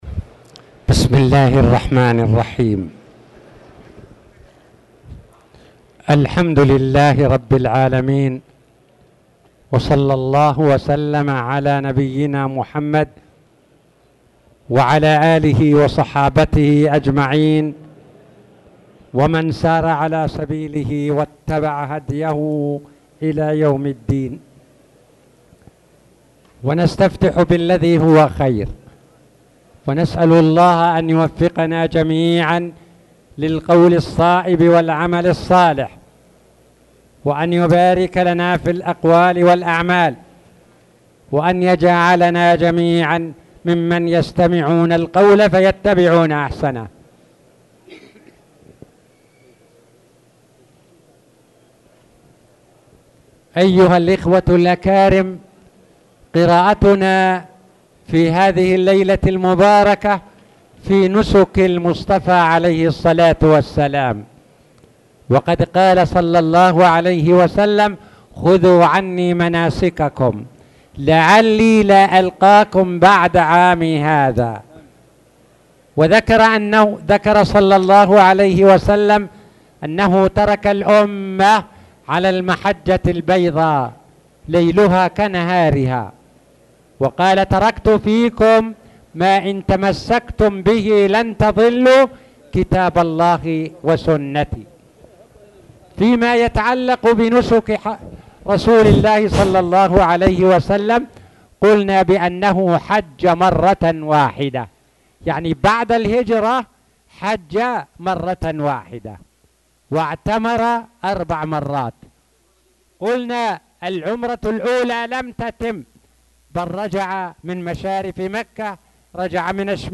تاريخ النشر ١٥ ذو القعدة ١٤٣٧ هـ المكان: المسجد الحرام الشيخ